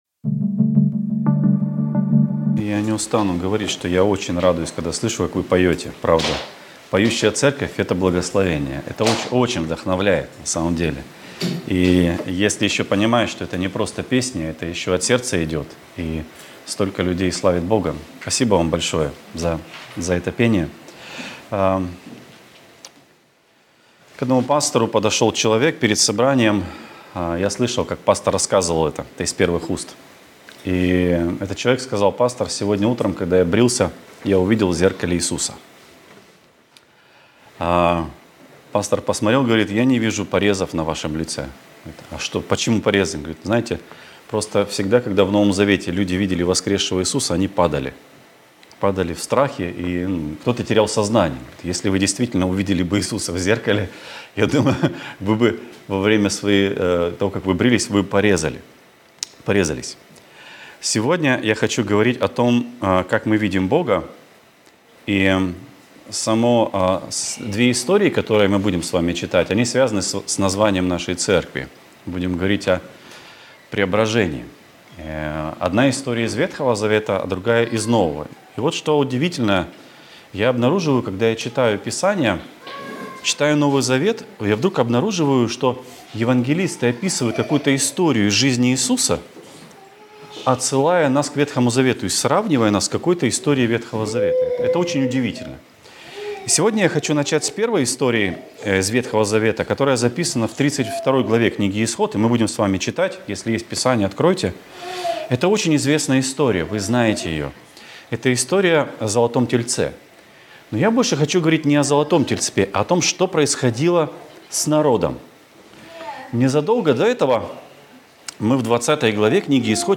«Преображение» | Церковь евангельских христиан-баптистов